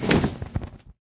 wings4.ogg